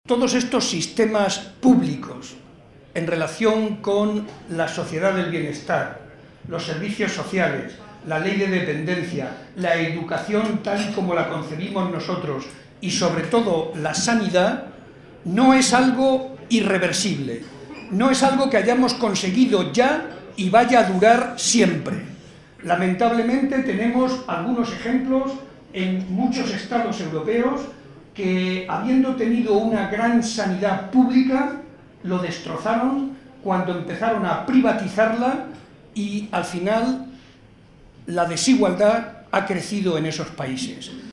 Estas palabras fueron pronunciadas por Barreda en el tradicional vino de Navidad organizado por la Agrupación Socialista de Ciudad Real, momento en el que aprovechó para reivindicar el papel fundamental de la educación para lograr los valores anteriormente mencionados.
Vino navideño PSOE Ciudad Real